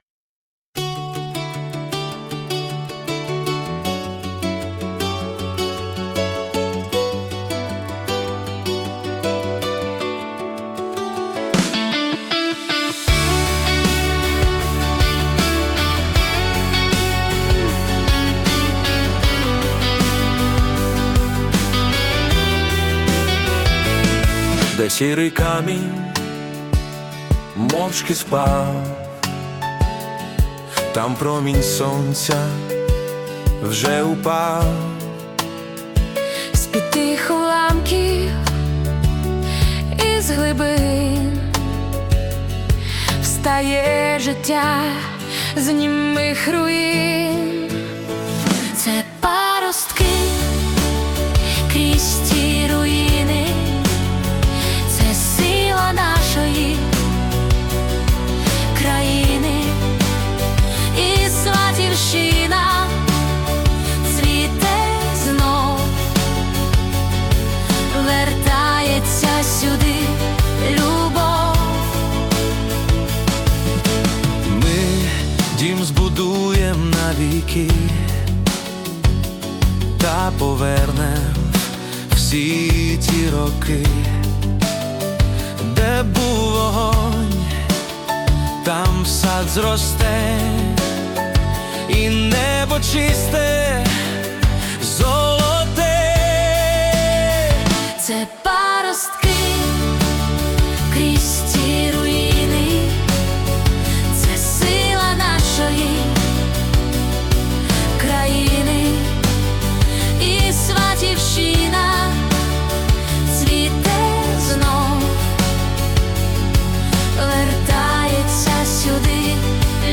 🎵 Жанр: Italo Disco / Synth-Pop